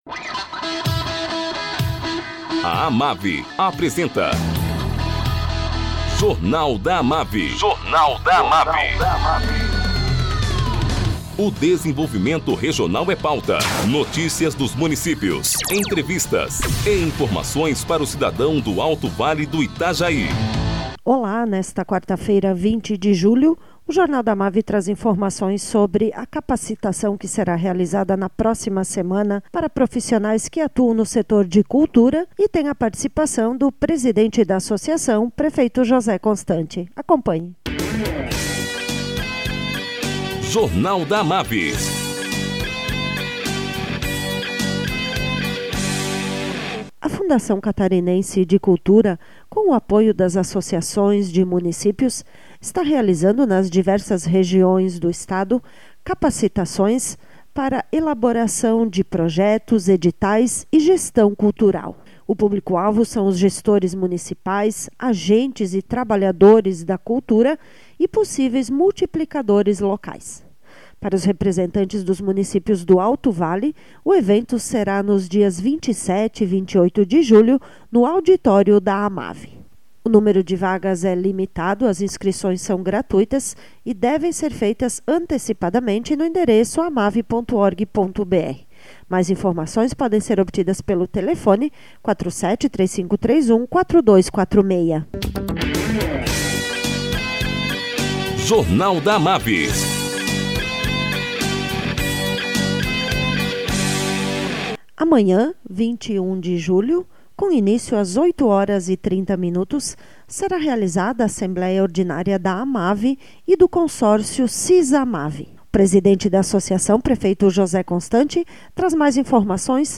Presidente da AMAVI, prefeito José Constante, fala sobre os assuntos que serão discutidos amanhã, na assembleia da AMAVI e do consórcio CISAMAVI.